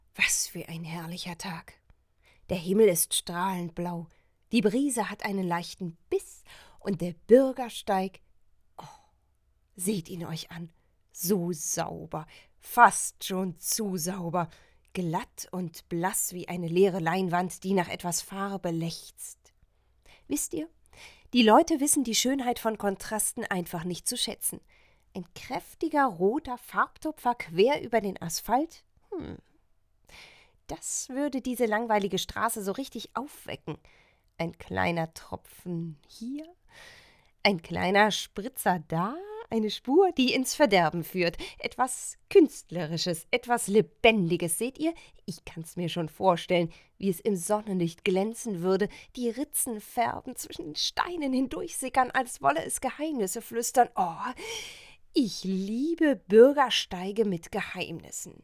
sehr variabel, hell, fein, zart, markant
Mittel minus (25-45)
Eigene Sprecherkabine
Tale
Audio Drama (Hörspiel), Audiobook (Hörbuch), Game, Tale (Erzählung)